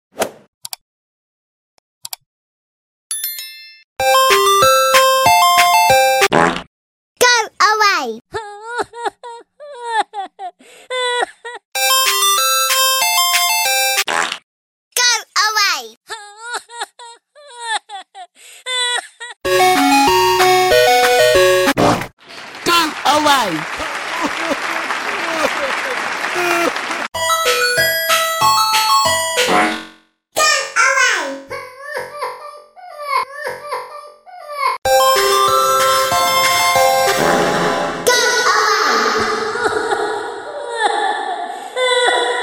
Pocoyo "Fart" & Ice Cream sound effects free download
Pocoyo "Fart" & Ice Cream Truck "Crying" Sound Variations in 43 Seconds